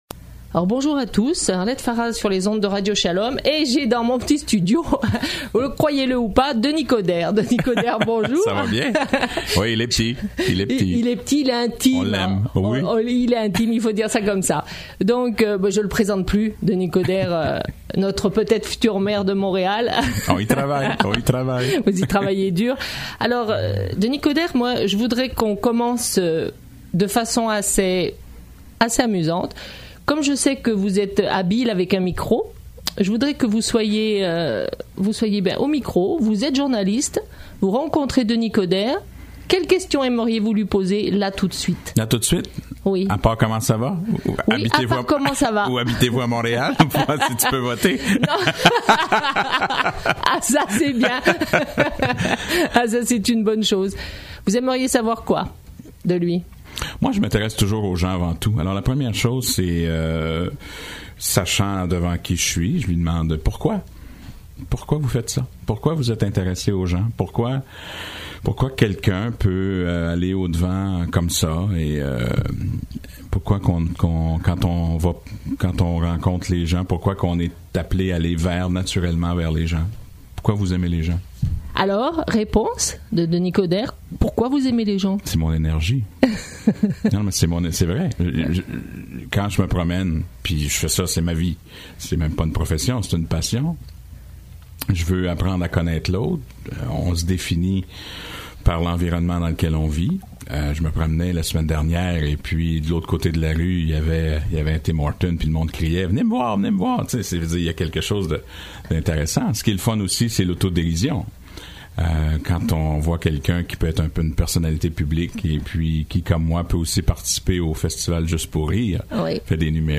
Entrevue avec Denis Coderre